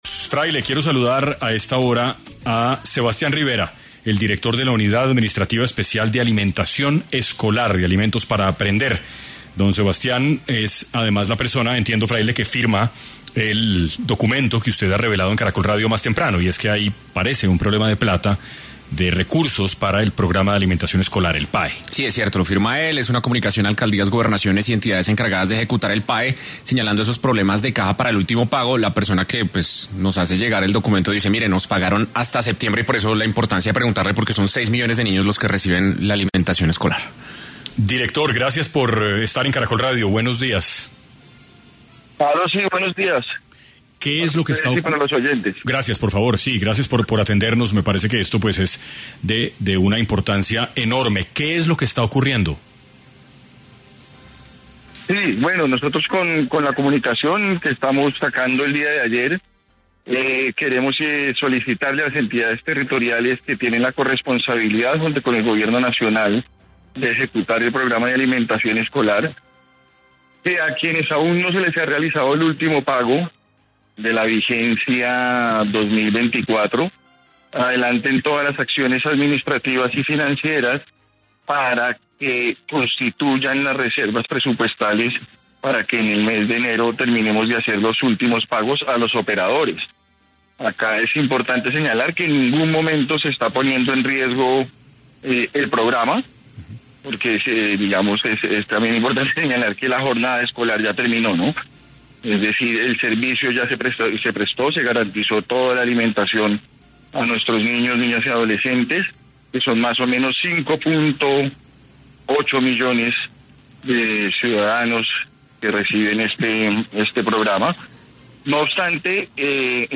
Sebastián Rivera, director de la Unidad Administrativa Especial de Alimentación Escolar estuvo en 6AM de Caracol Radio y aclaró qué sucederá con el presupuesto del PAE para el 2025.